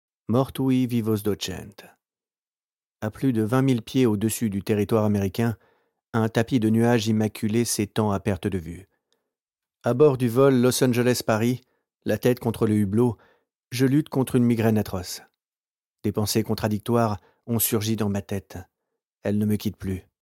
Narrateur de livre audio pour votre production de livre audio.